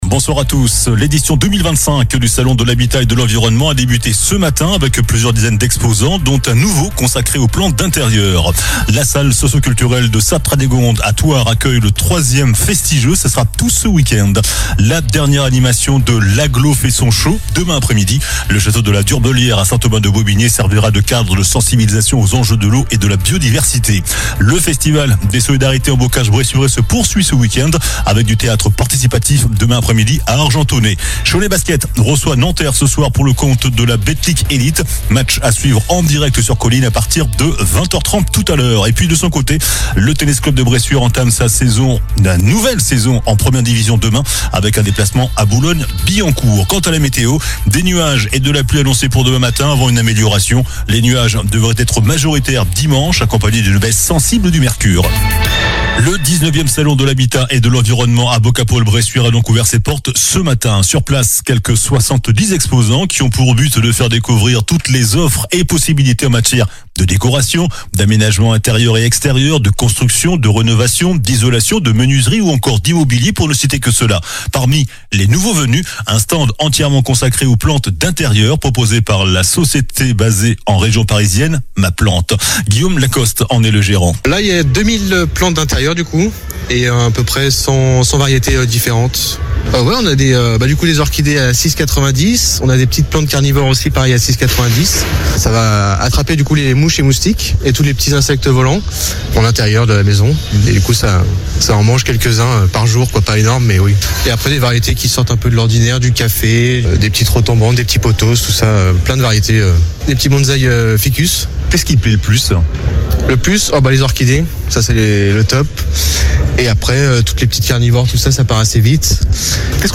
Journal du vendredi 14 novembre (soir)